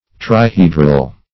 Search Result for " trihedral" : The Collaborative International Dictionary of English v.0.48: Trihedral \Tri*he"dral\, a. [See Trihedron .]